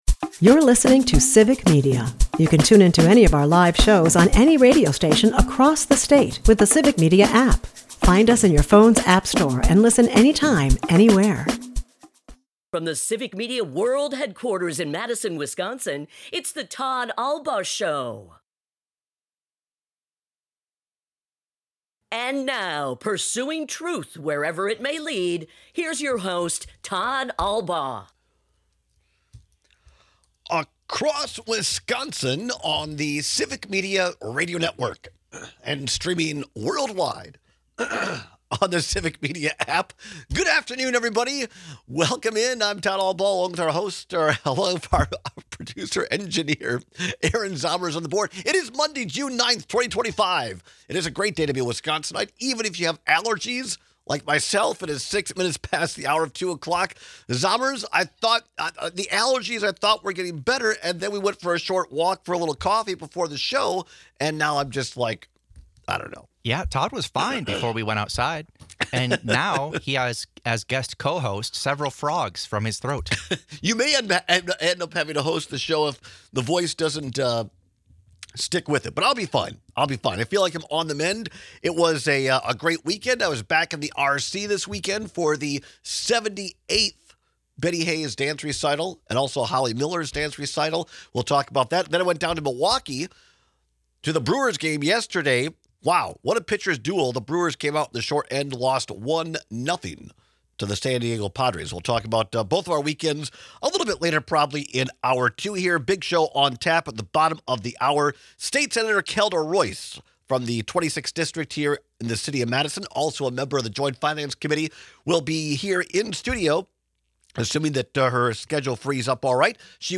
At the bottom of the hour, State Senator Kelda Roys joins us for another update on the Joint Finance Committee’s state budget negotiations.